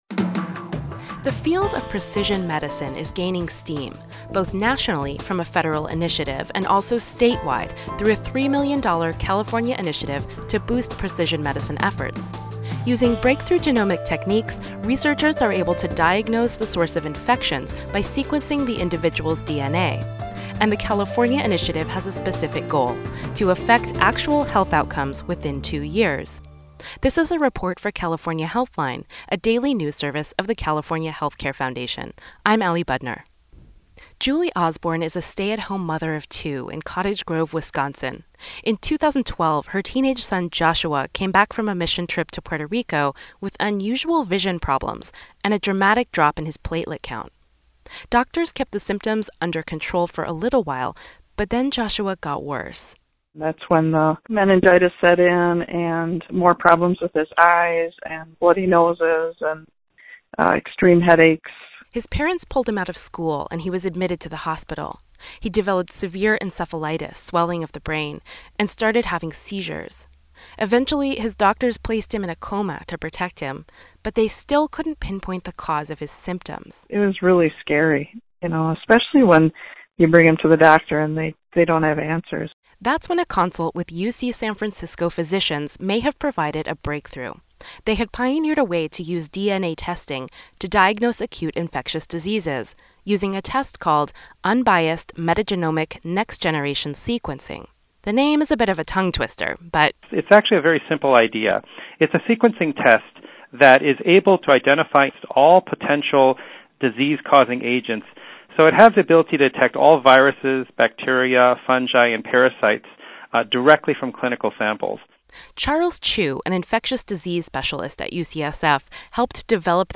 The report includes comments from:
• California Gov. Jerry Brown (D);
Audio Report Insight Multimedia